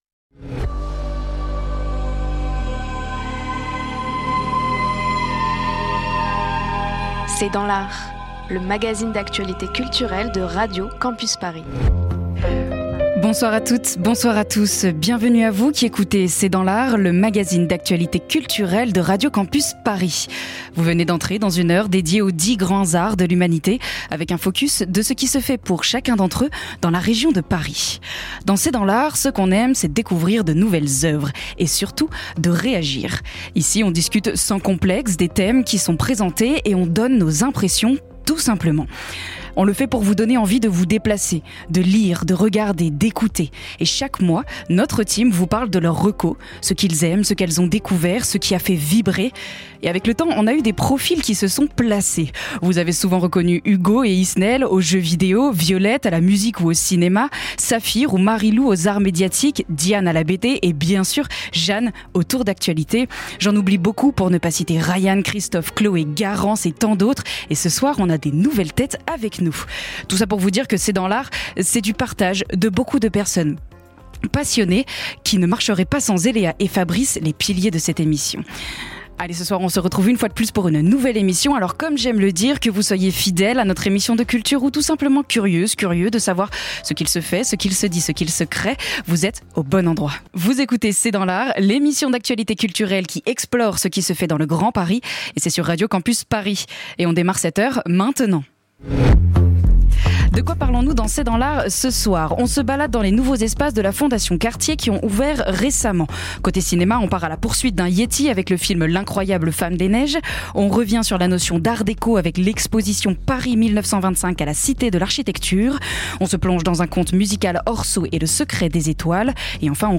C’est dans l’art, l’émission d'actualité culturelle de Radio Campus Paris.